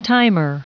Prononciation du mot timer en anglais (fichier audio)
Prononciation du mot : timer